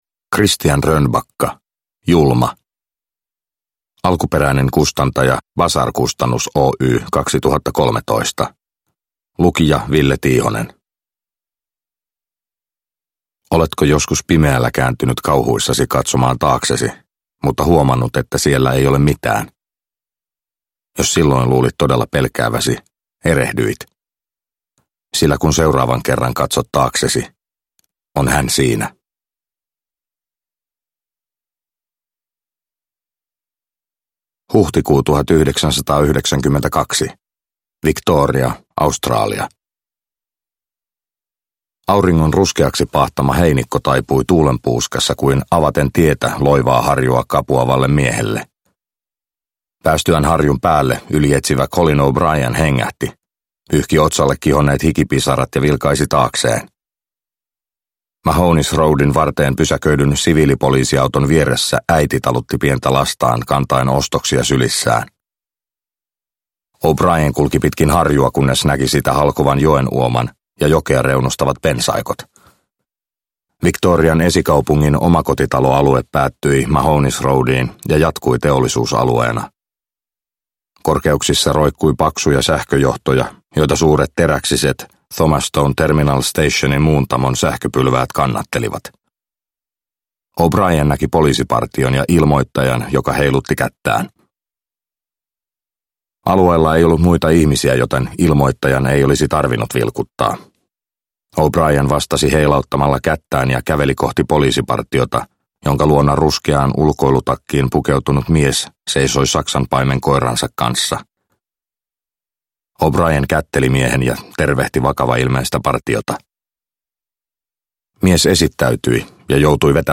Julma – Ljudbok – Laddas ner